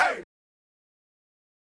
Vox
Z.T.Gvox_folder-0db__DrummaA.wav